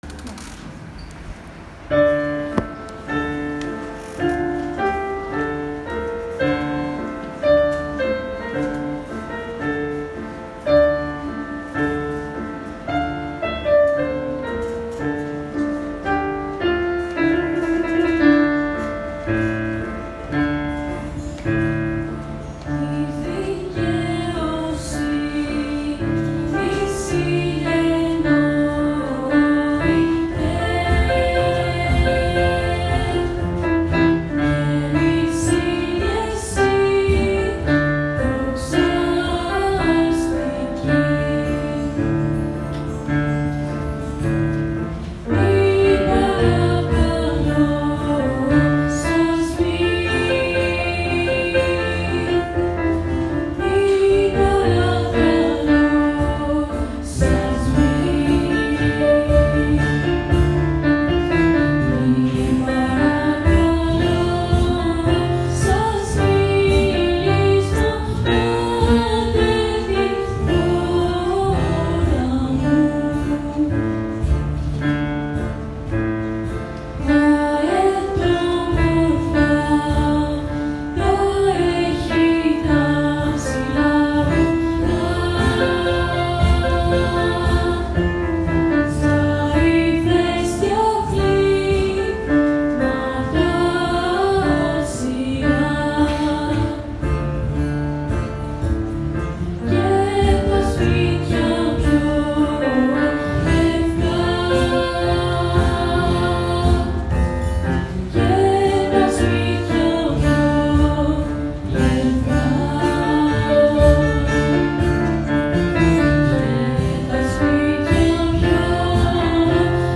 Αποσπάσματα από τη σχολική γιορτή για την επέτειο του Πολυτεχνείου